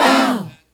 Bow W Dip-A.wav